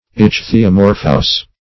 Search Result for " ichthyomorphous" : The Collaborative International Dictionary of English v.0.48: Ichthyomorphic \Ich`thy*o*mor"phic\, Ichthyomorphous \Ich`thy*o*mor"phous\, a. [See Ichthyomorpha .]
ichthyomorphous.mp3